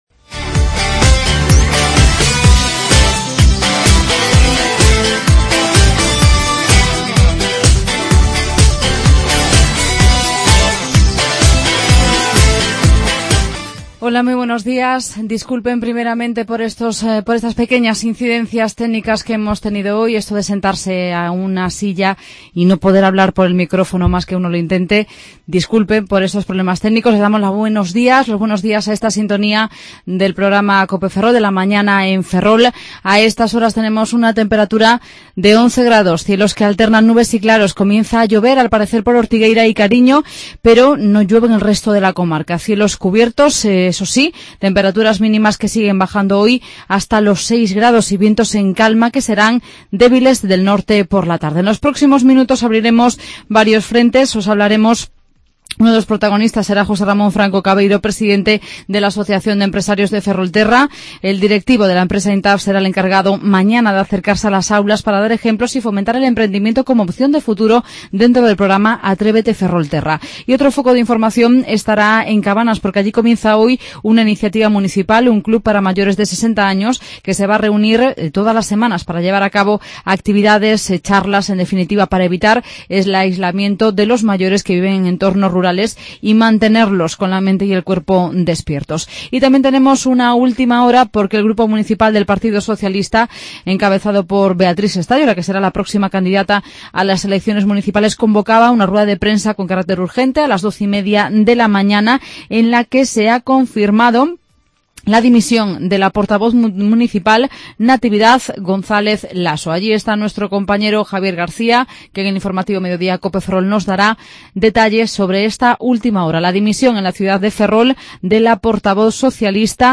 AUDIO: En el magazine de hoy charlamos con las hermanas Oblatas y nos acercaremos a Cabanas para hablar de la puesta en marcha del Club + 60